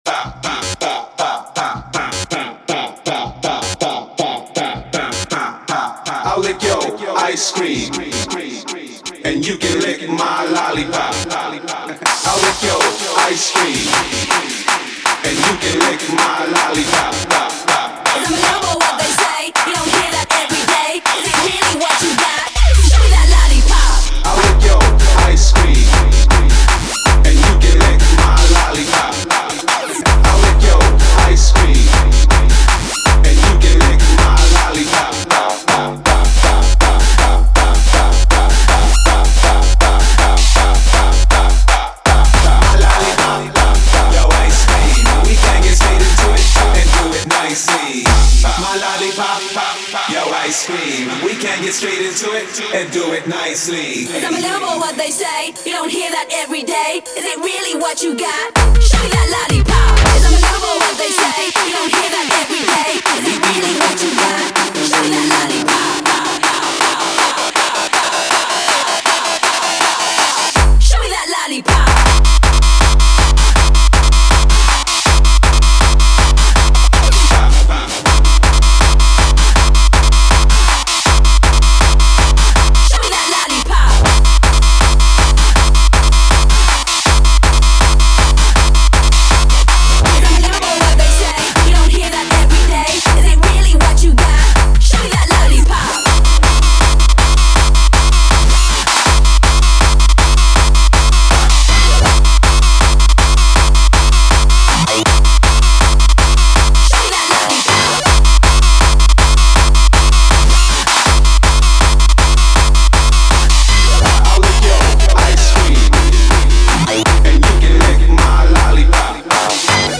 Género: Newstyle.